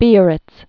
(bēə-rĭts, bēə-rĭts)